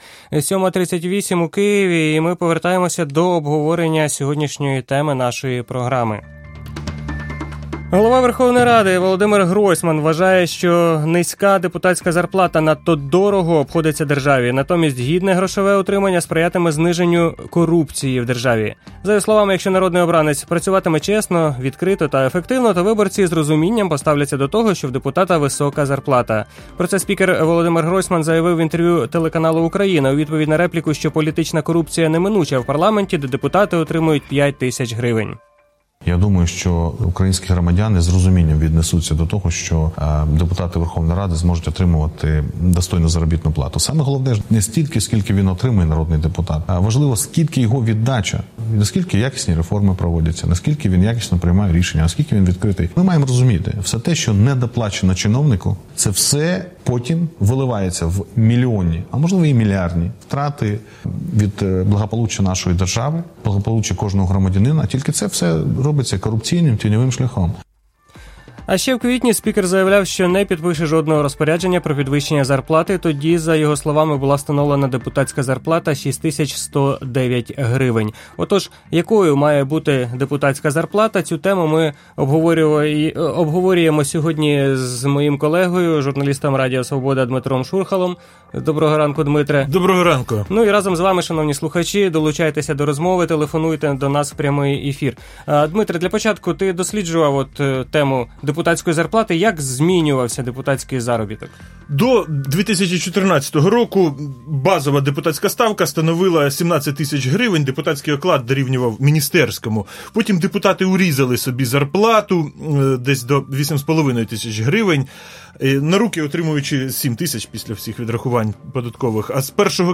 Про зарплати народних депутатів, про те, чи сприятиме зниженню рівня корупції їх підвищення, йшлося в ефірі Ранкової Свободи.